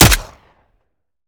weap_decho_sup_plr_01.ogg